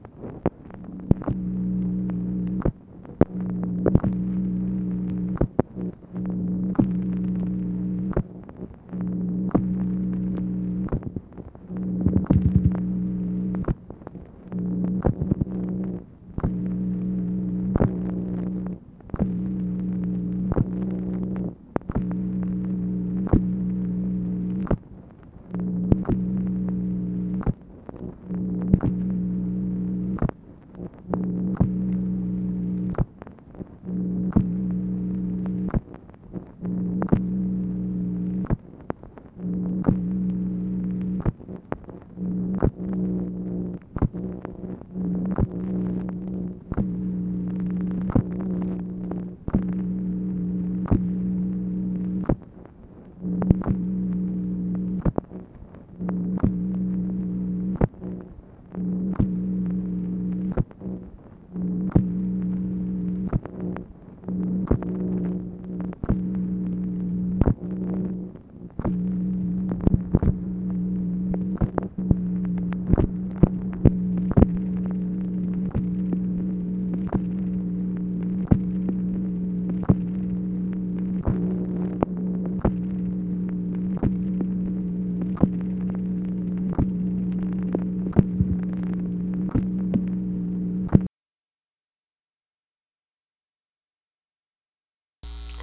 MACHINE NOISE, February 6, 1964
Secret White House Tapes | Lyndon B. Johnson Presidency